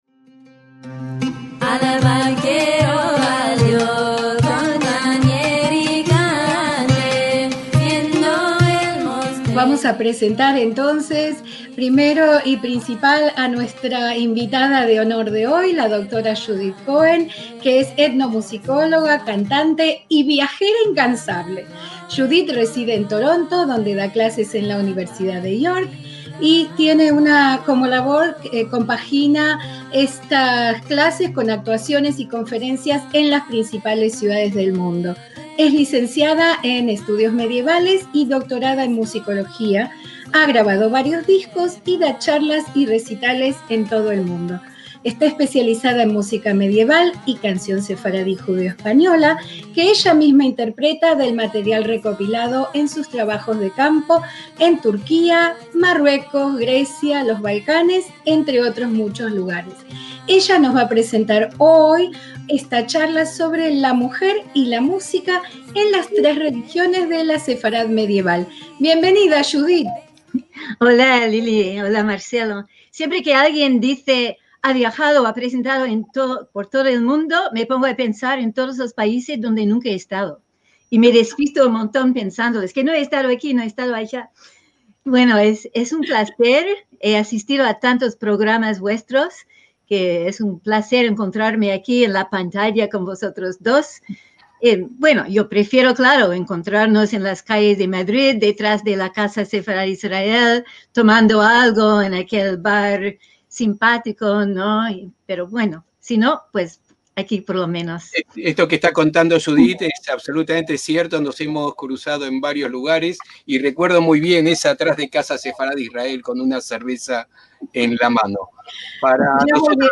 ACTOS "EN DIRECTO"